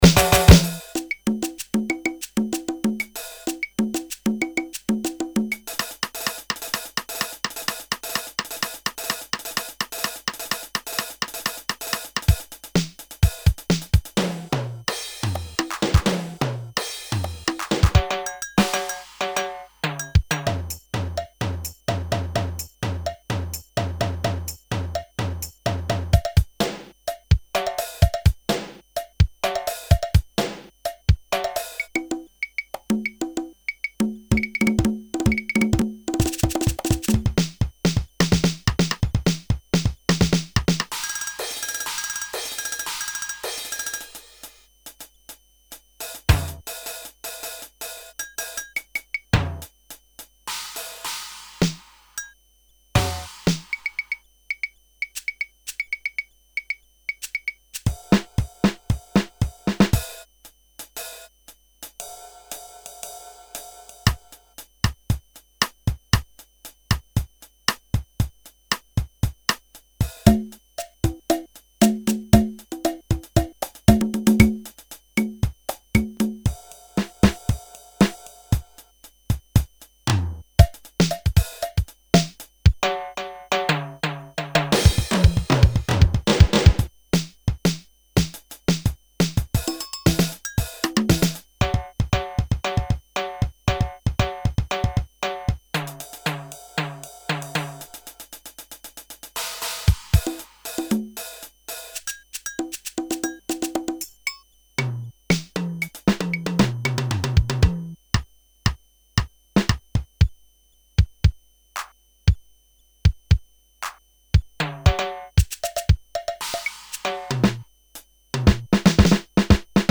One of the first fully-digital affordable drum machine based on compresed PCM acoustic samples.
some raw rhythm pattern